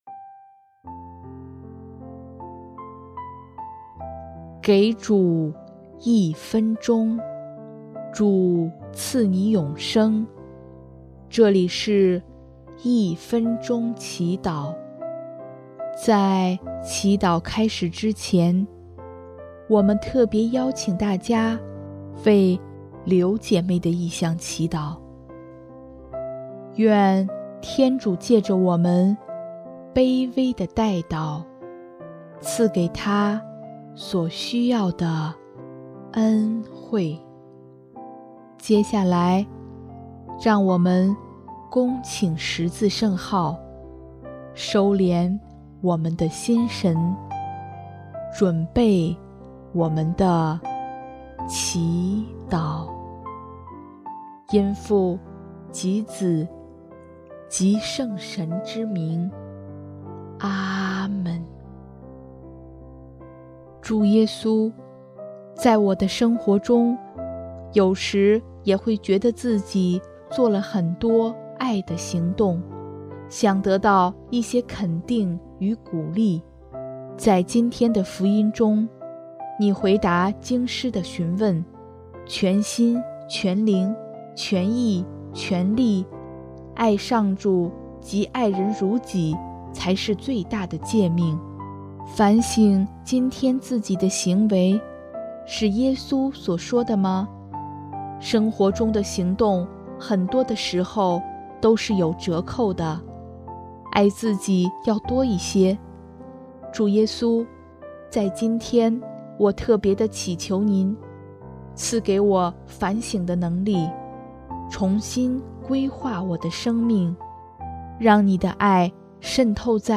【一分钟祈祷】|11月3日 圆满的爱德